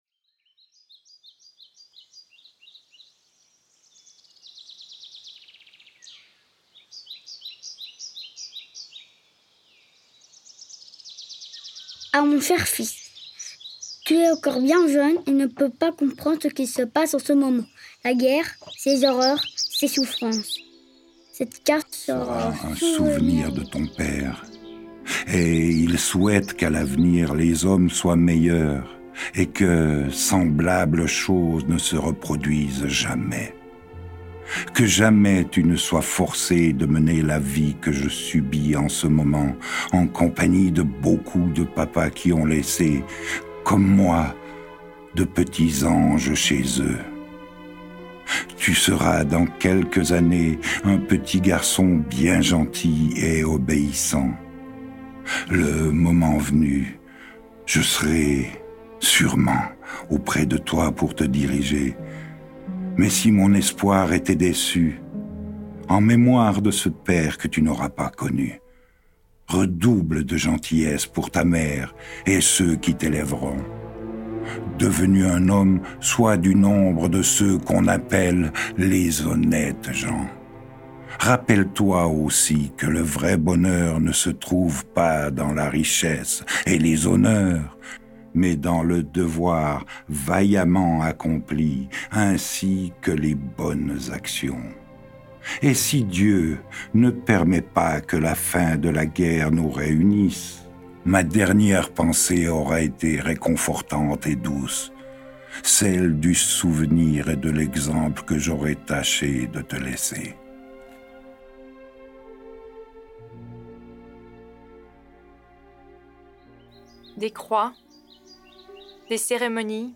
Affiche Extrait sonore Chaumont - 2013 Promenade dans les ruelles de Chaumont (Haute-Savoie, France) et reconstitution historique sonore transposant les participants à l’époque de la Première Guerre mondiale.